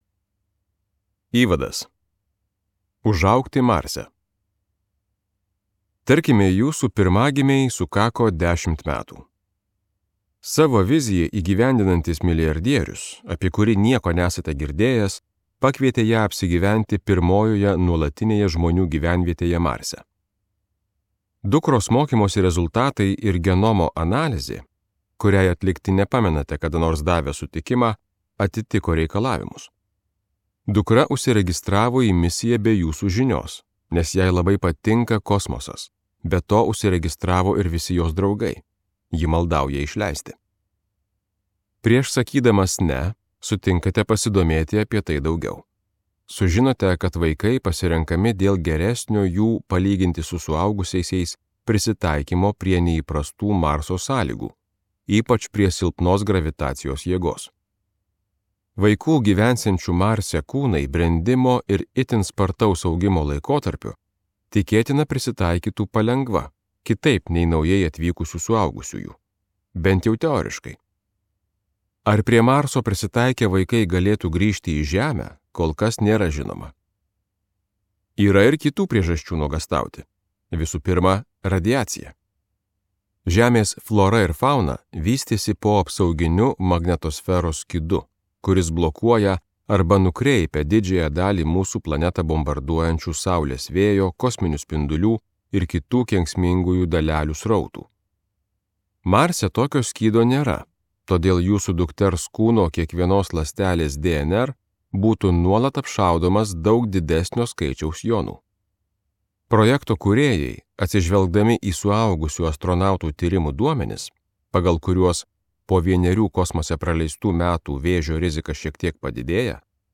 Nerimo karta | Audioknygos | baltos lankos